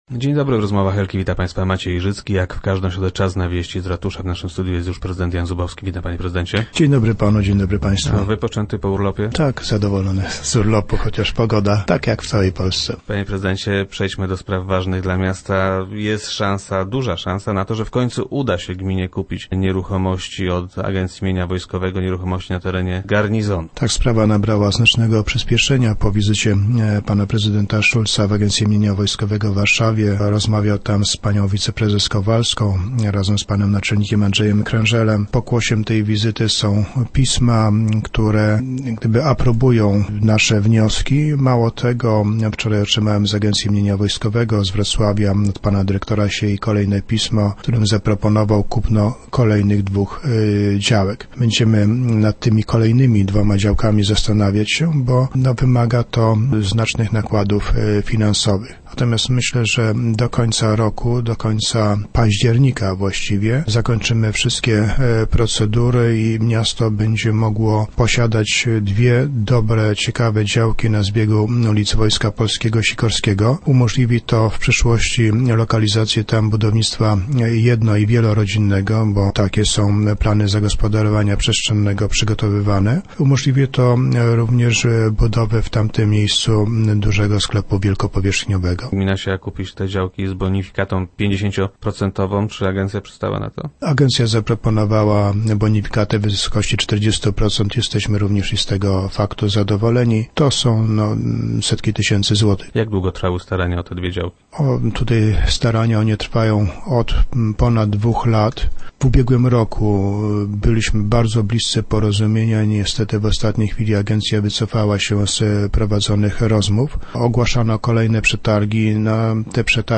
- Pokłosiem tej wizyty są pisma, w których władze agencji aprobują nasze wnioski. Mało tego. We wtorek dotarło do ratusza także pismo, w którym dyrektor agencji zaproponował kupno dwóch kolejnych działek. Będziemy się nad tym zastanawiali - powiedział nam prezydent Zubowski, który był gościem środowych Rozmów Elki.